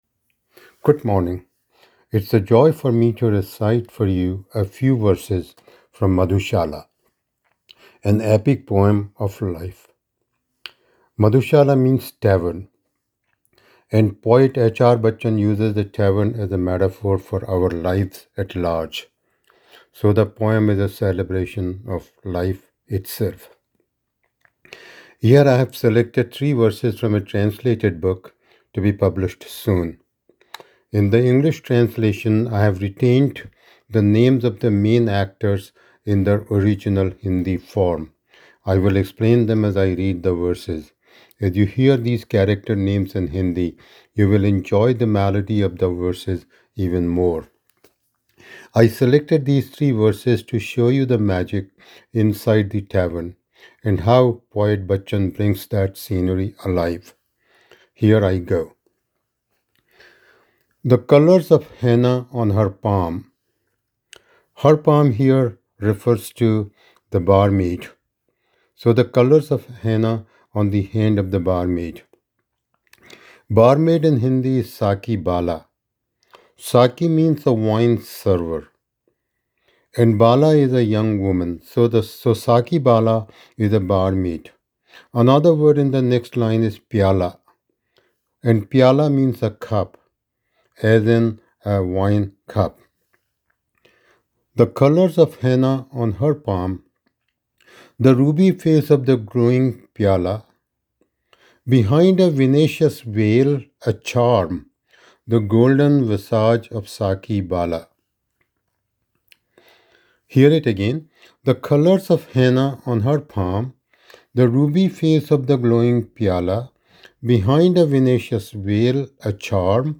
My_madhu_recital_sampler.m4a